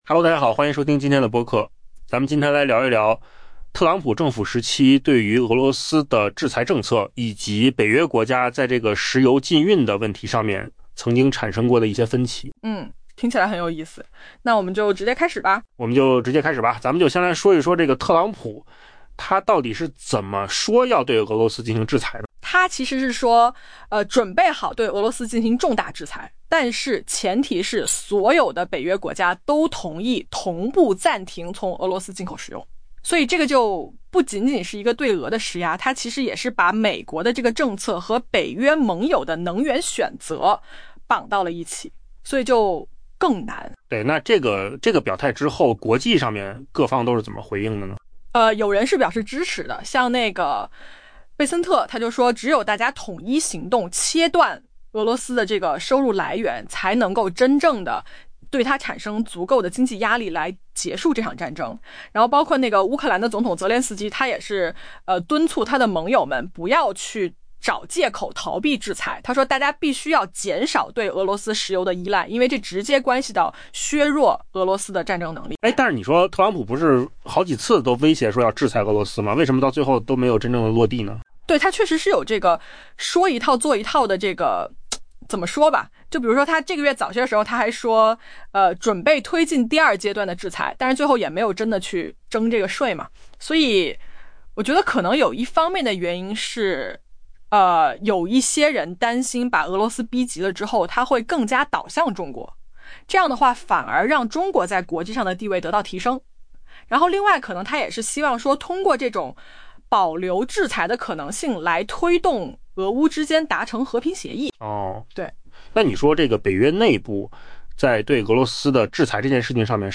AI播客：换个方式听新闻.
音频由扣子空间生成